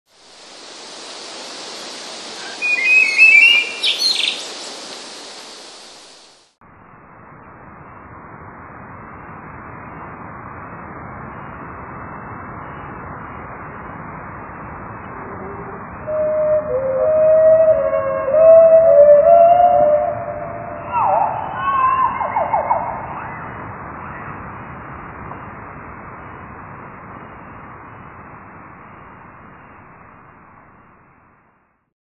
melodyjny, jednak melodia jest znacznie mniej czysto zagwizdana niż w przykładzie poprzednim. Tak jak w poprzednim przykładzie druga część nagrania jest zwolniona i obniżona.